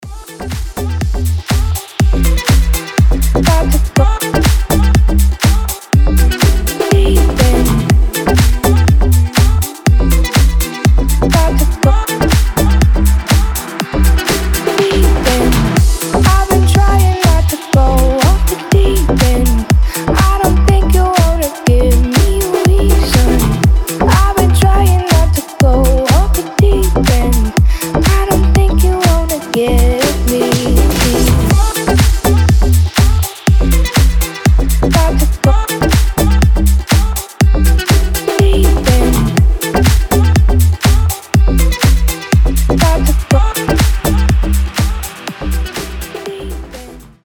deep house
мелодичные
чувственные
красивый женский голос
ремиксы